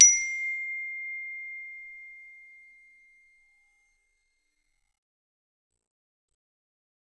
glock_medium_C6.wav